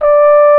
360 FL HOR.1.wav